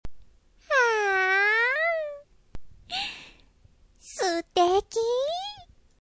１４歳/女性
サンプルボイス